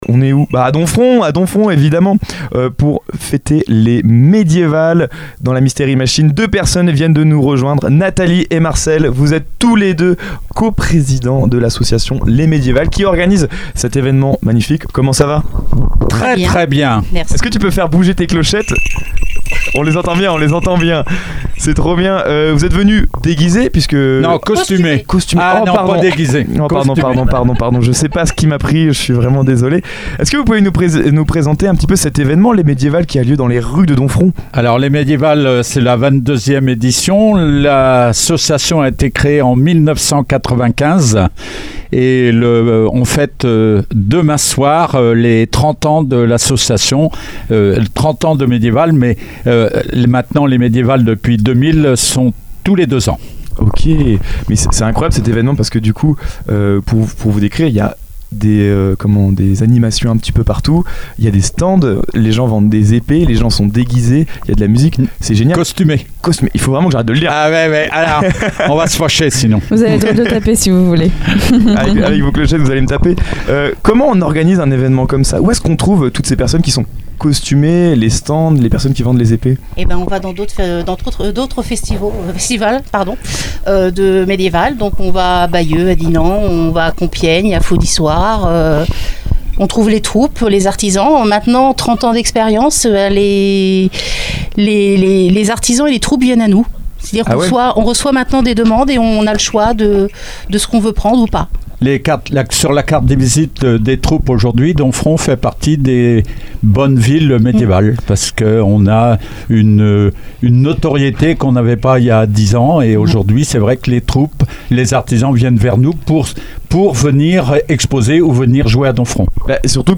Ils évoquent la préparation de l’événement, l’engagement des bénévoles et la passion qui anime l’association pour faire revivre l’histoire au cœur des remparts de Domfront. Une interview vivante et immersive qui met en lumière l’énergie collective et l’attachement au patrimoine qui font vibrer les Médiévales de Domfront chaque année.